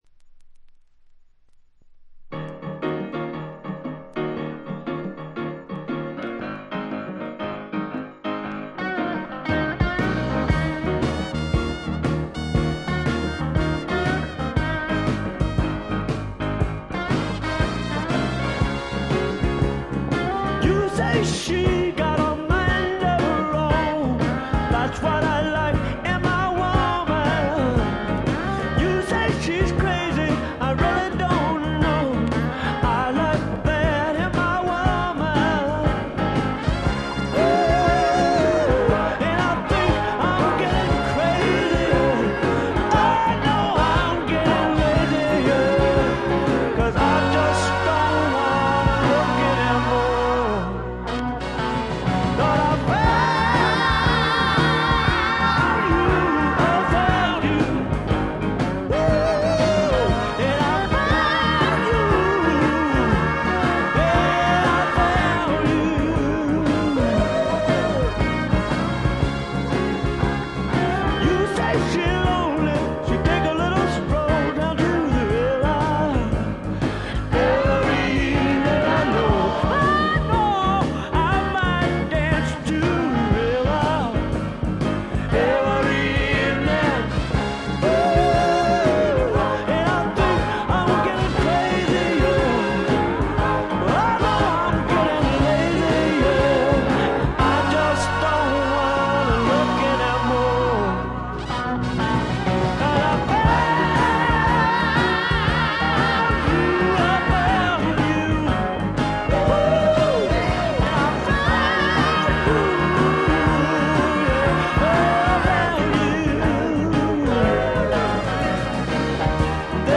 泣けるバラードからリズムナンバーまで、ゴスペル風味にあふれたスワンプロック。
試聴曲は現品からの取り込み音源です。